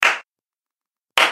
potatOS voice